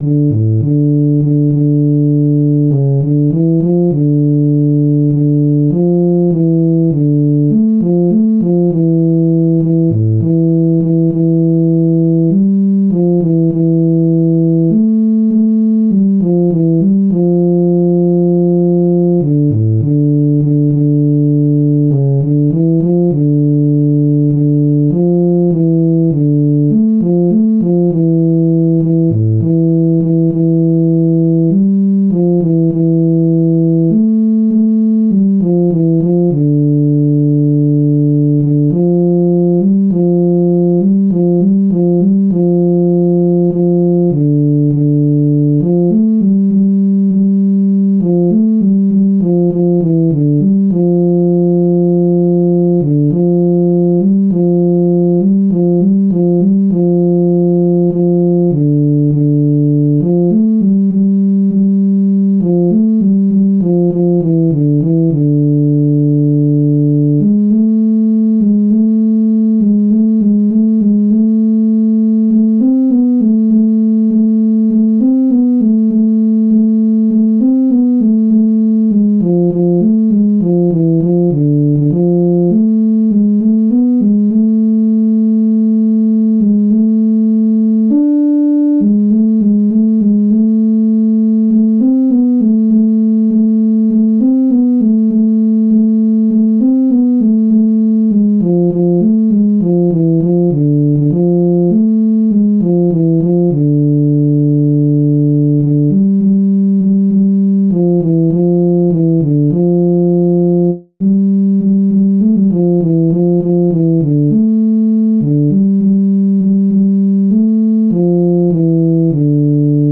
Jewish Folk Song (Tehillim 85: Chabad-Lubavitch melody)
D minor ♩= 100 bpm